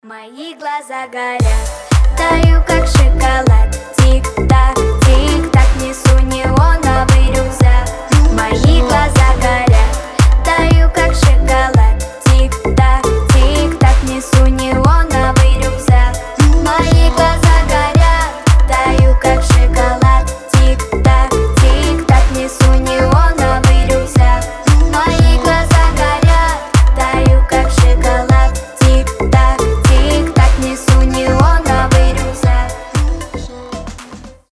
• Качество: 320, Stereo
позитивные
веселые
заводные
teen pop